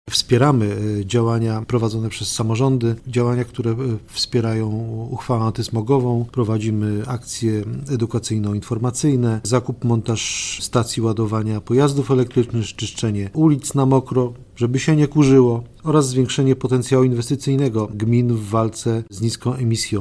Wicemarszałek woj. mazowieckiego Wiesław Raboszuk podkreśla, że ten program daje realne narzędzia do poprawy jakości powietrza: